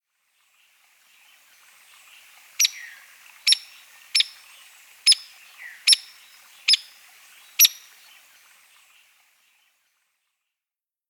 Hairy Woodpecker
How they sound: The most common call of the Hairy Woodpecker is a short, sharp peeknote, but they are also well known for the sounds they make as they drum against wood or metal.